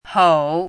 “吽”读音
hǒu
hǒu.mp3